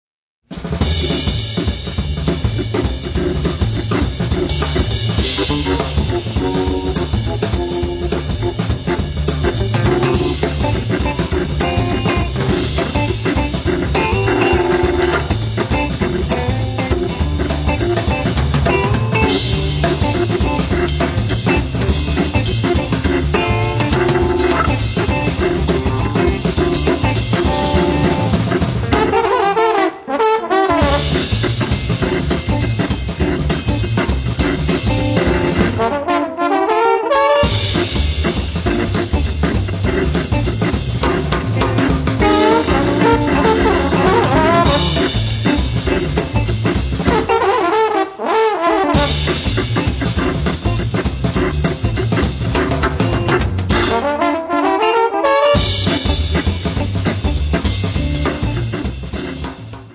trombone
chitarra
Hammond b3 organ
batteria